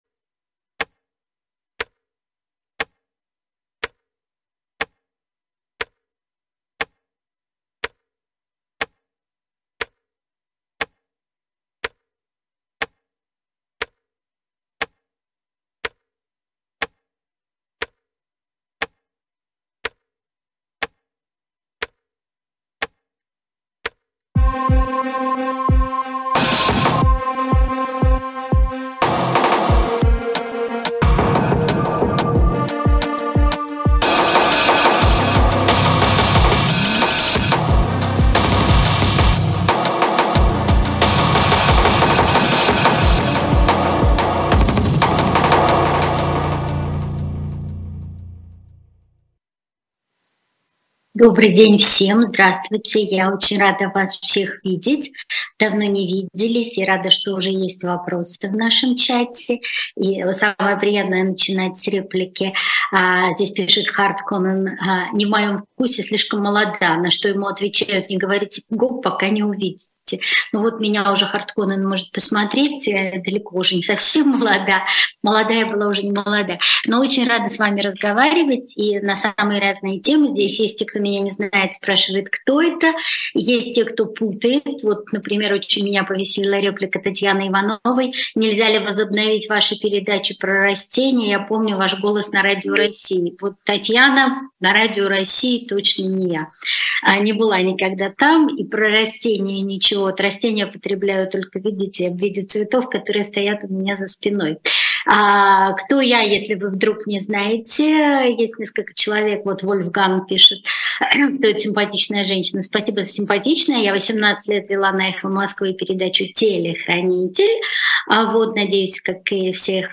отвечает на вопросы слушателей